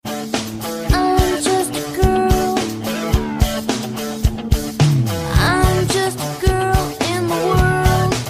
im just a girl Meme Sound Effect